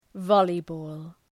Προφορά
{‘vɒlıbɔ:l} (Ουσιαστικό) ● βόλεϊ